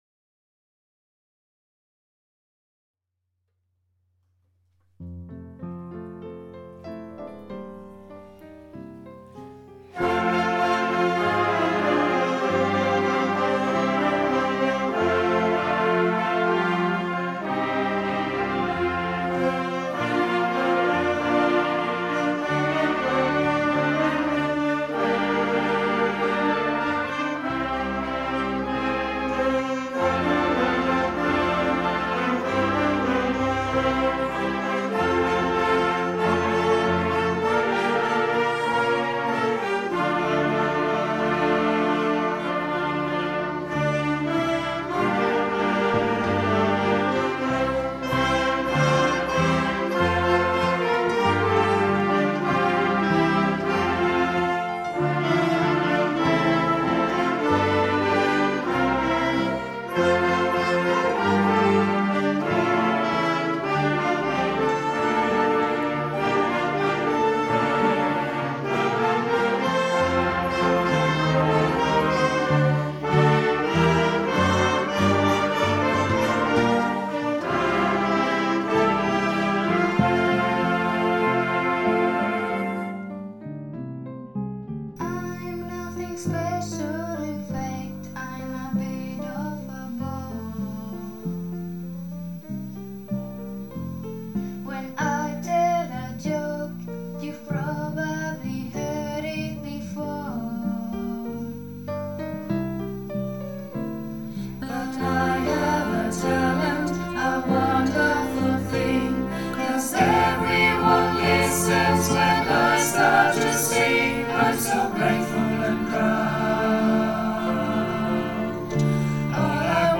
Die Deutsche Schule Santa Cruz de Tenerife und ihre Schulgemeinschaft „leben“ auch während der Schulschließung weiter und halten zusammen!
• Der Fachbereich Musik präsentiert sein großes musikalisches Finale des Schuljahrs 2019/20:
Das digitale Schüler-Eltern-Lehrer-Orchester und Chor der DST spielt „Thank You for the Music“ von ABBA.
Digitales-Schüler-Eltern-Lehrer-Orchester-und-Chor-der-DST_Thank-You-for-the-Music.mp3